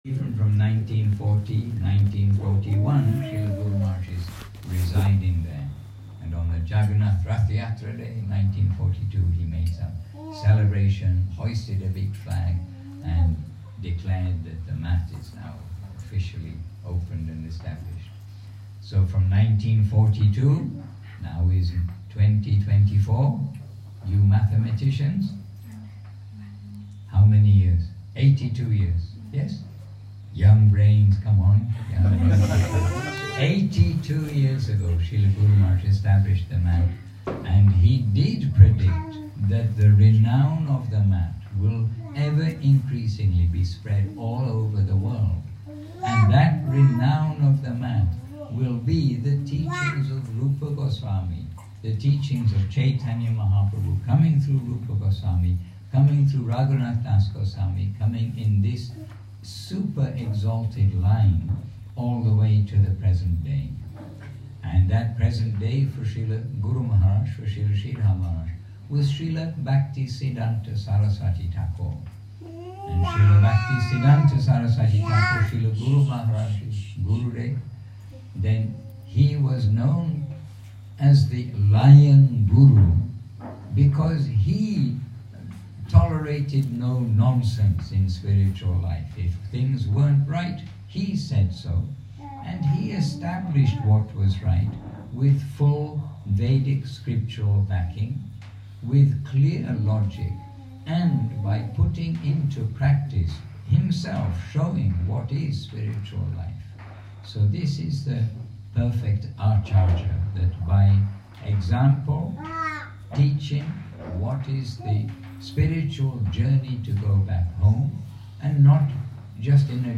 Introductory meeting.
Первоначальная встреча.